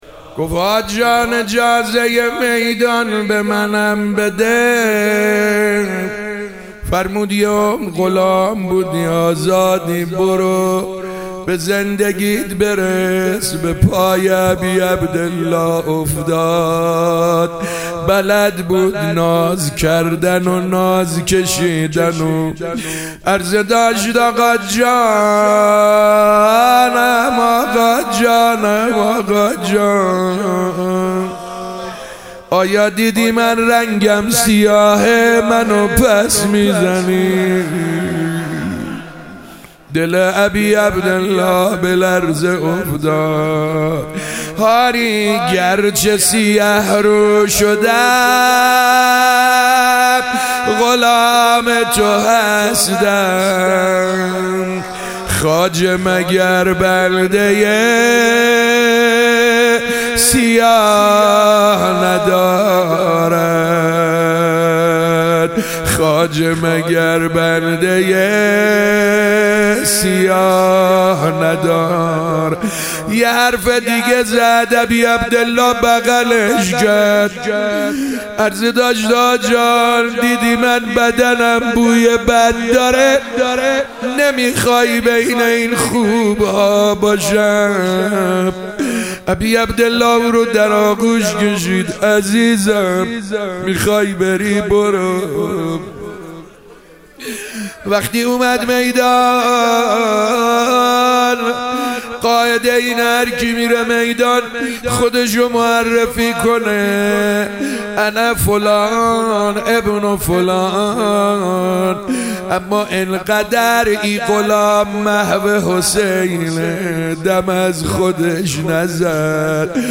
شب هشتم محرم 97 - روضه - جون غلام امام حسین علیه السلام